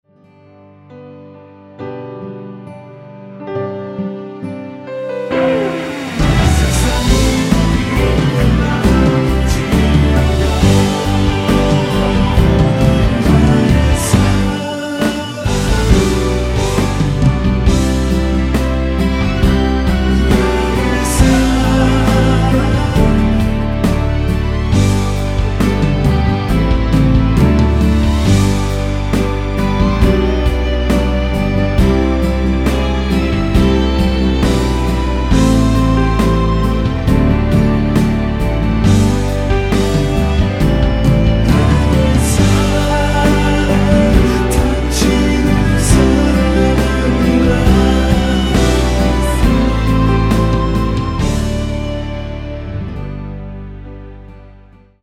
(-2) 내린코러스 포함된 MR 입니다.(미리듣기 참조)
앞부분30초, 뒷부분30초씩 편집해서 올려 드리고 있습니다.